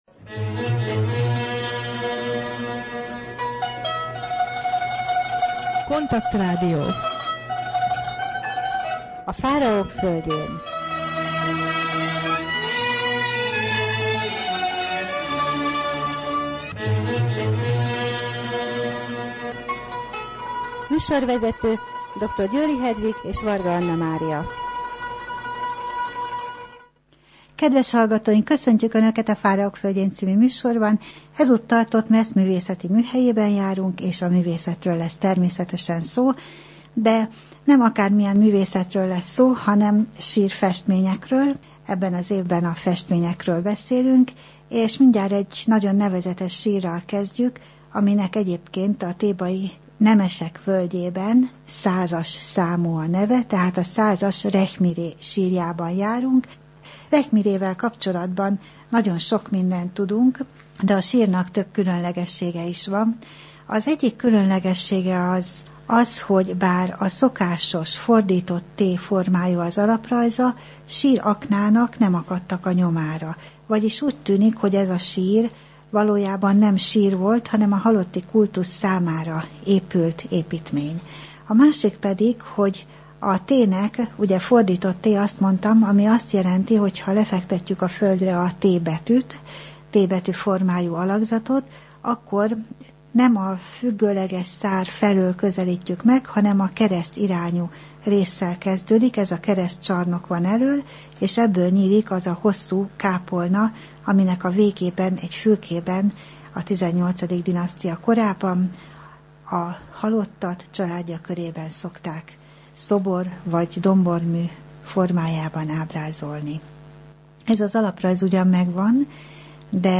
Rádió: Fáraók földjén Adás dátuma: 2015, April 3 Thotmesz Művészeti Műhely / KONTAKT Rádió (87,6 MHz) 2015. április 3. A műsor témája Rekhmiré sírja (TT100), a vezír teendői, pékség, adók, műhelyek, II. Amenhotep koronázása, tigrismogyoró, temetési menet Részlet Rekhmiré sírjából: tigrismogyorós sütemény készítése forrás: N.G. Davies, The Tomb of Rekh-mi-re at Thebes, Metropolitan Museum, 1943, pl.